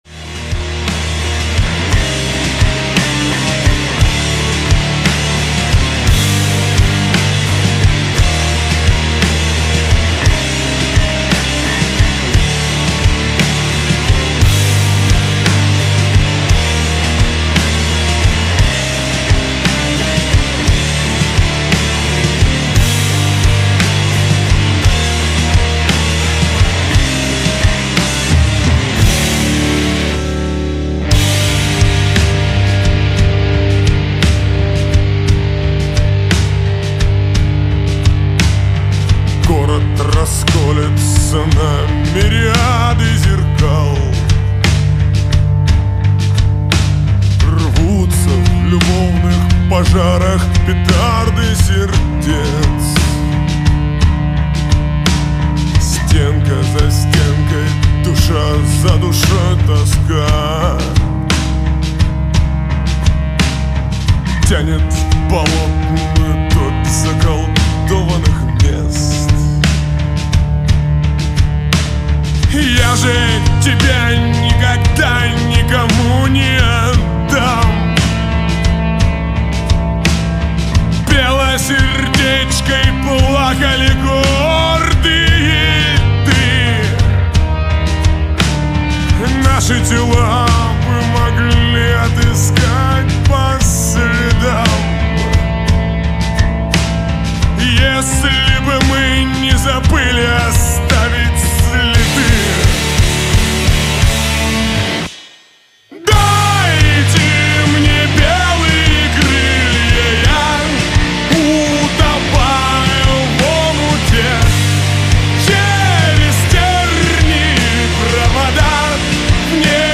панк група
но здесь она как-то "приторможенна"